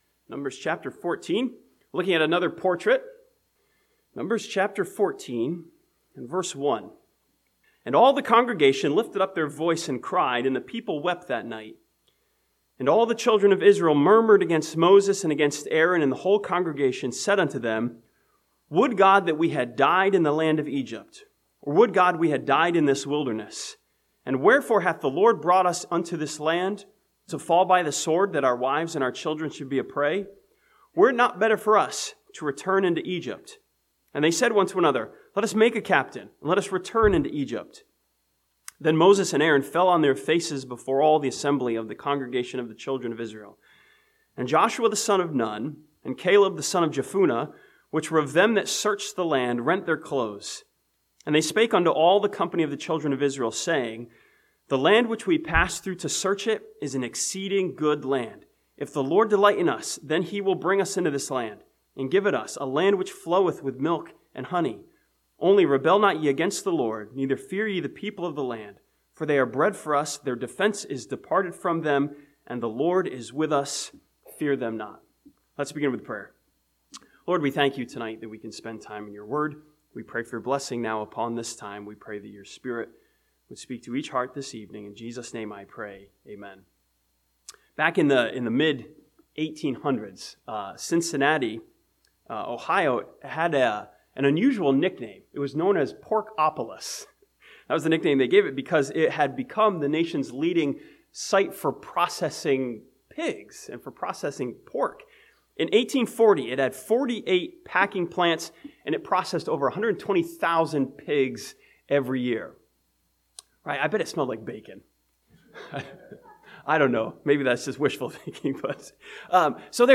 This sermon from Numbers chapter 14 looks at Caleb as the portrait of a visionary who saw something the other spies did not see.